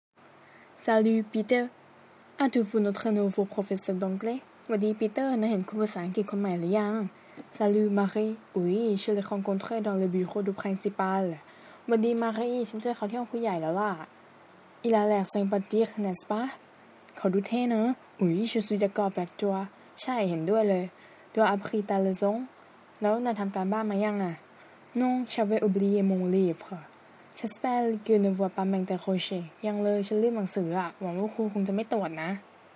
บทสนทนา13